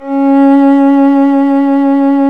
Index of /90_sSampleCDs/Roland - String Master Series/STR_Violin 1-3vb/STR_Vln1 _ marc